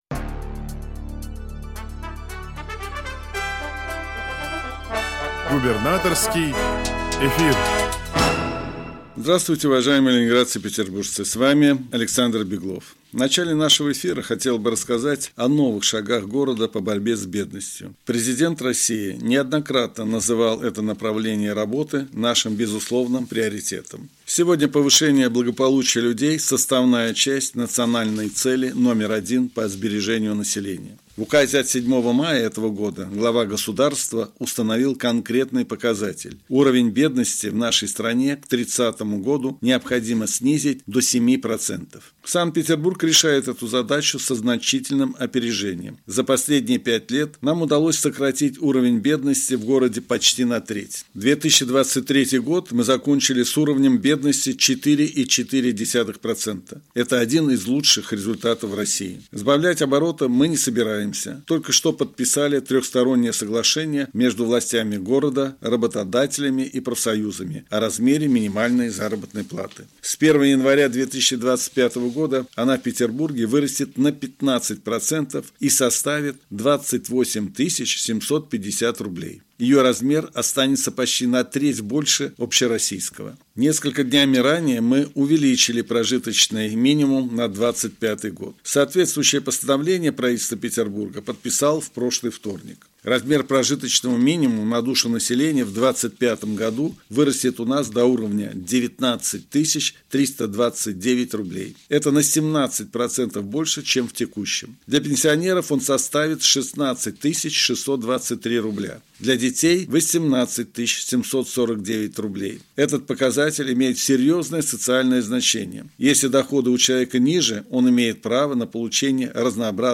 Радиообращение – 19 августа 2024 года